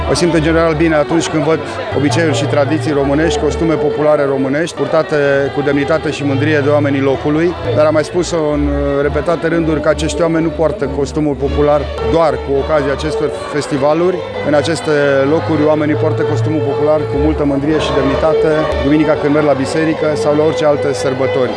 Prefectul judeţului Mureş, Lucian Goga, a observat bucuria cu care oamenii poartă costumul popular la festivalurile de tradiţii şi în zilele de sărbătoare: